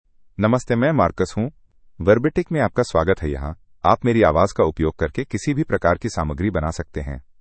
Marcus — Male Hindi (India) AI Voice | TTS, Voice Cloning & Video | Verbatik AI
Marcus is a male AI voice for Hindi (India).
Voice sample
Listen to Marcus's male Hindi voice.
Male
Marcus delivers clear pronunciation with authentic India Hindi intonation, making your content sound professionally produced.